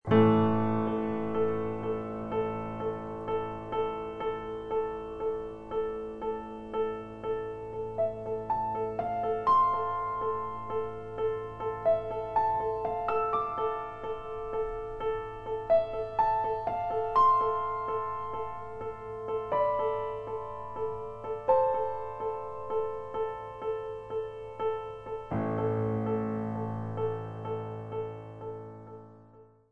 (solo piano)